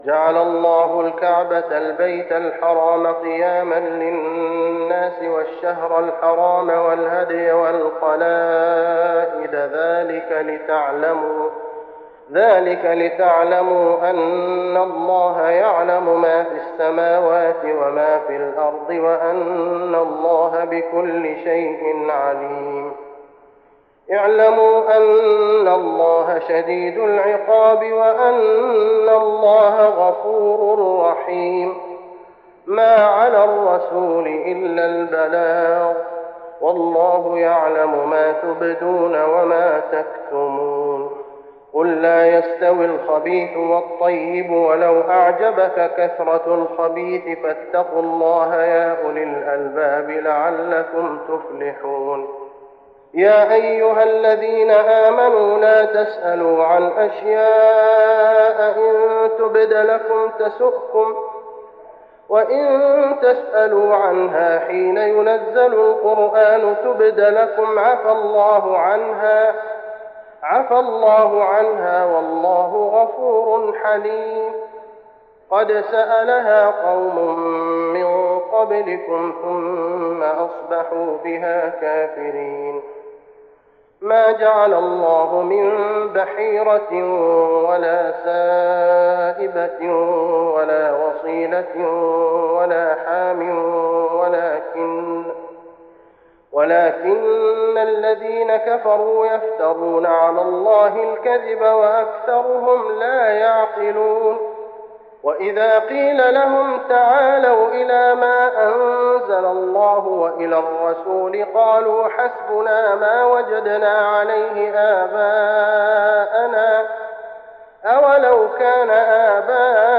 تراويح رمضان 1415هـ من سورة المائدة (97-120) و الأنعام (1-58) Taraweeh Ramadan 1415H from Surah AlMa'idah and Al-An’aam > تراويح الحرم النبوي عام 1415 🕌 > التراويح - تلاوات الحرمين